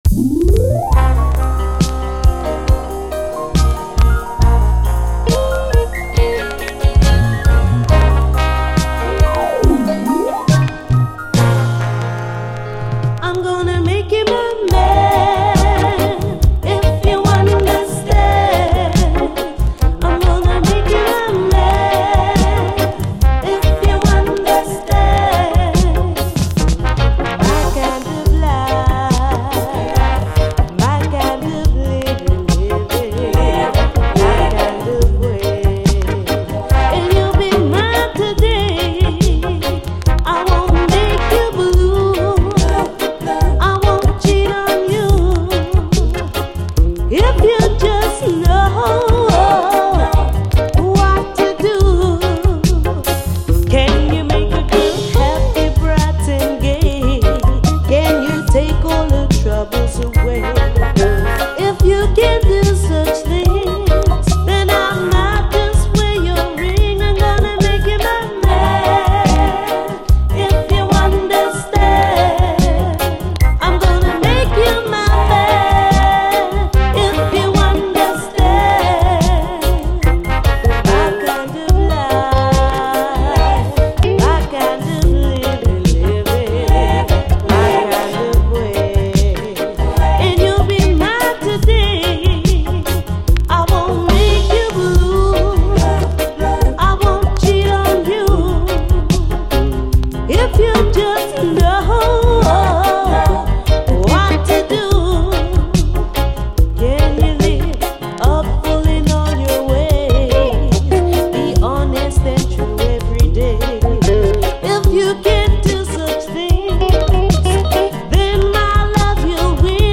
REGGAE
ビターなムードが渋いUKラヴァーズ10インチ！
盤見た目はEX-くらいですがチリパチノイズ有
後半はダブ。